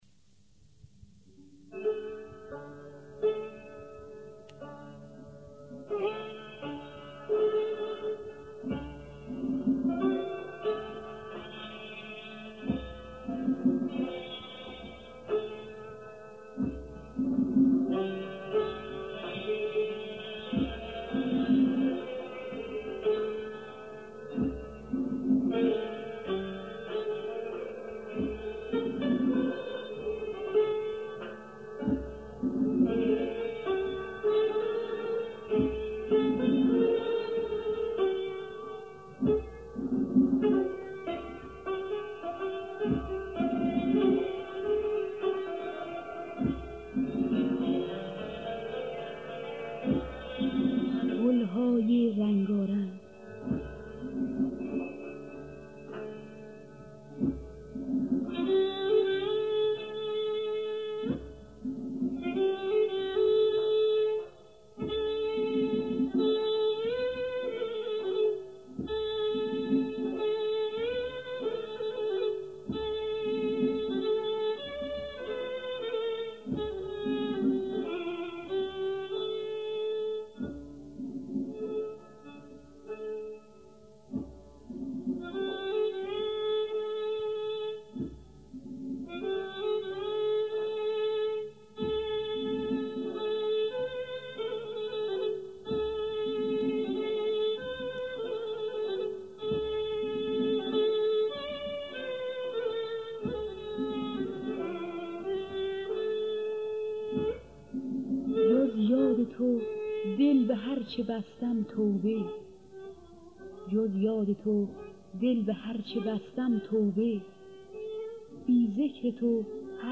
خوانندگان: مرضیه